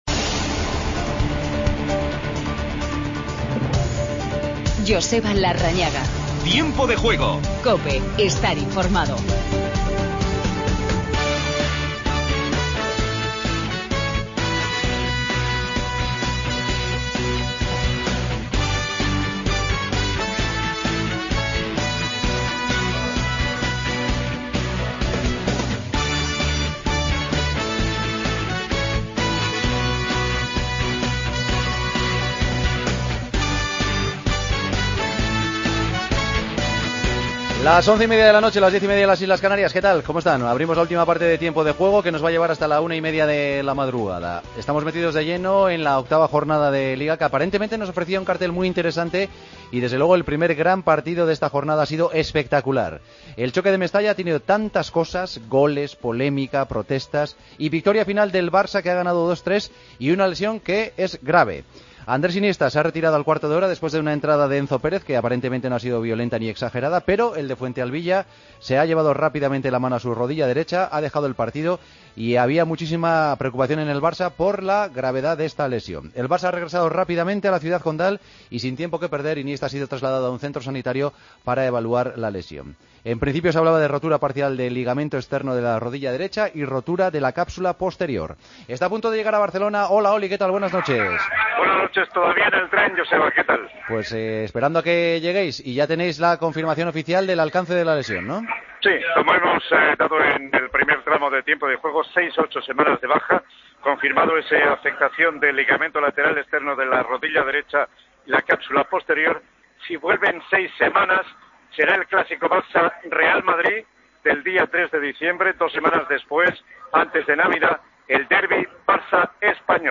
Entrevista a Rodrigo Moreno. Escuchamos a Busquets. Tiempo de Juego Con Paco González, Manolo Lama y Juanma Castaño